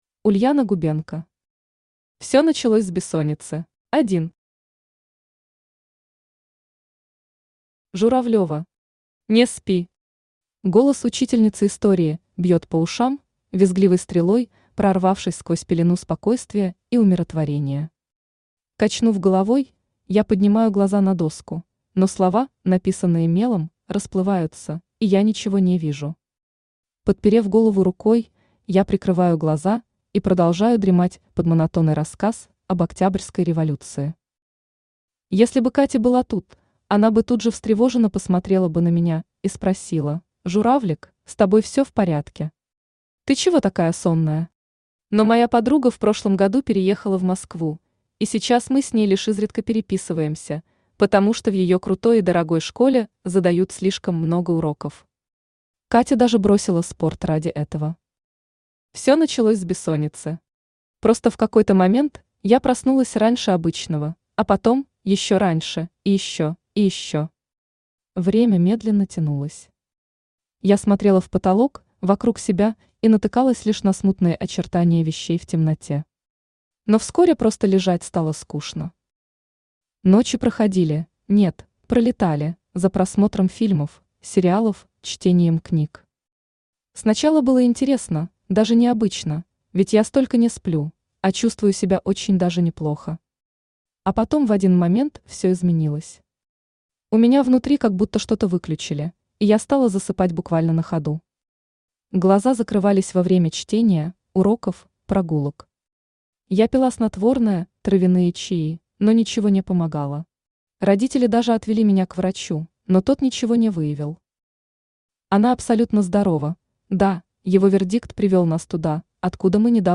Аудиокнига Всё началось с бессонницы | Библиотека аудиокниг
Aудиокнига Всё началось с бессонницы Автор Ульяна Губенко Читает аудиокнигу Авточтец ЛитРес.